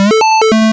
retro_synth_beeps_02.wav